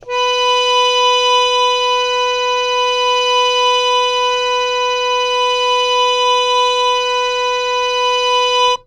interactive-fretboard / samples / harmonium / B4.wav
B4.wav